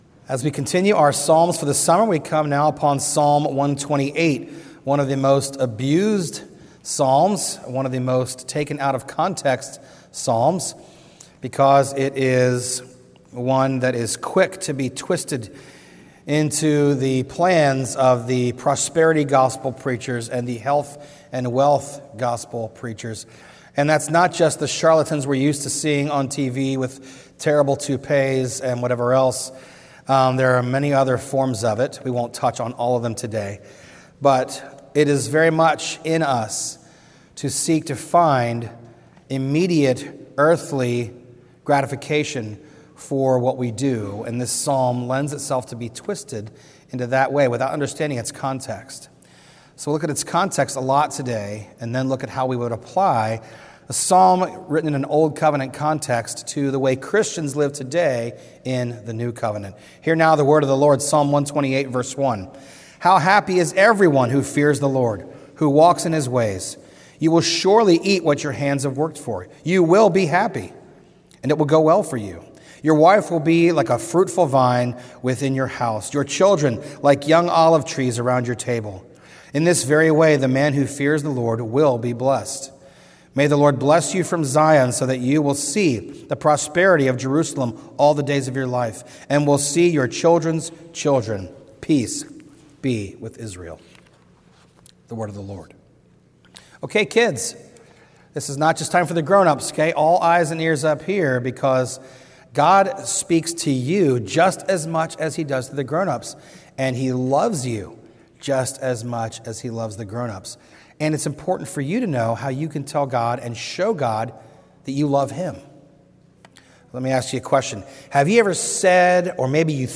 A message from the series "Psalms (Summer 2023)."